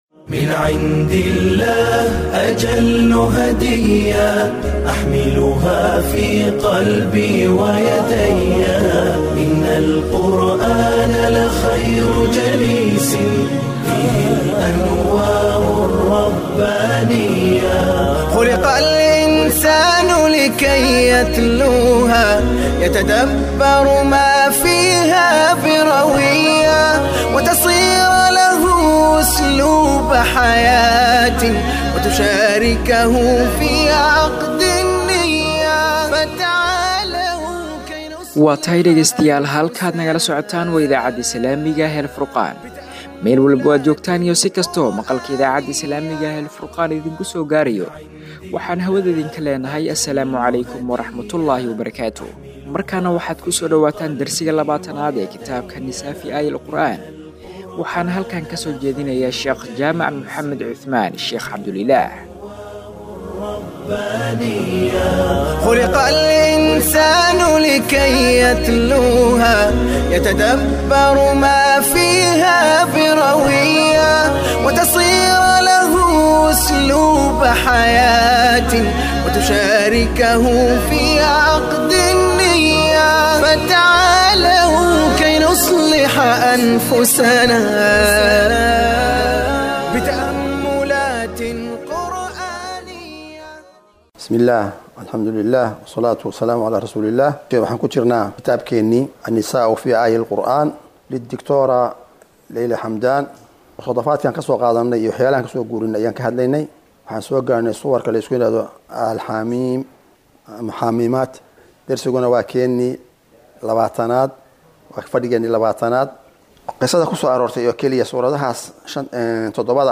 Dersiga 20aad